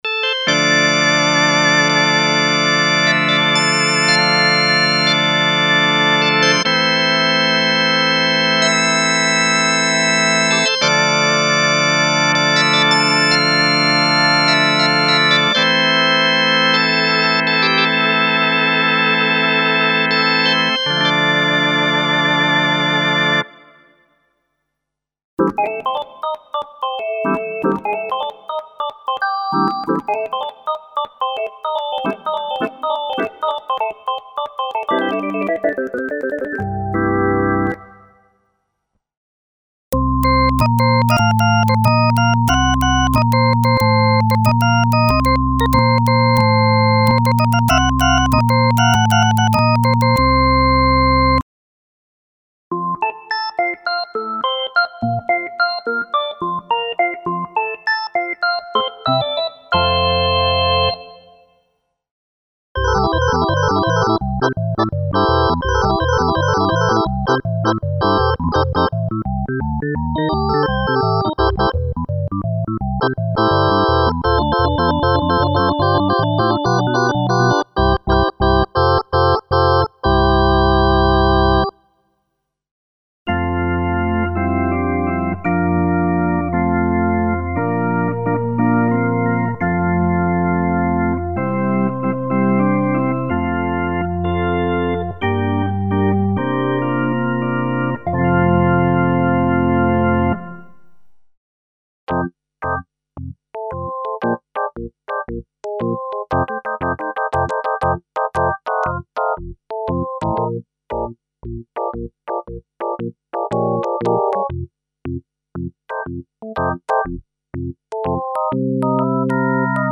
Electric organ, organ split and layers for various music styles, including a complete KB 3 Mode collection of drawbar organ emulations.
Info: All original K:Works sound programs use internal Kurzweil K2500 ROM samples exclusively, there are no external samples used.